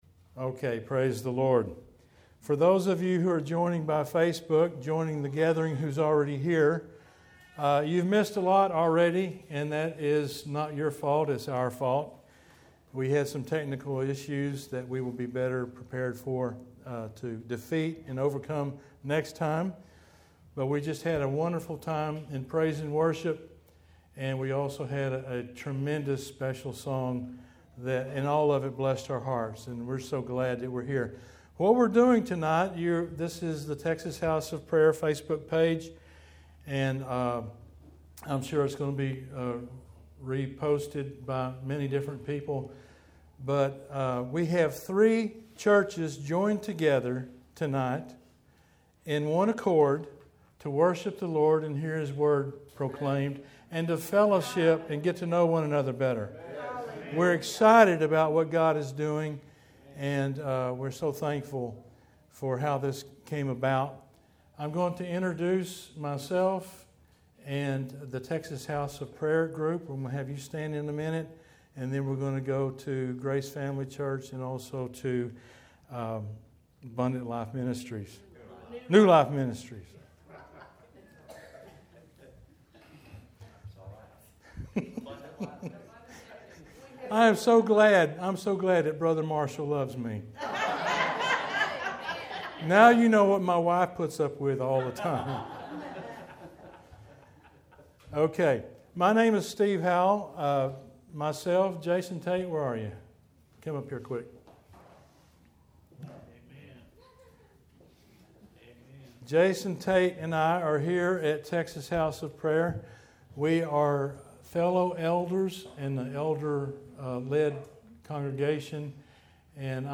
Joint Service At THOP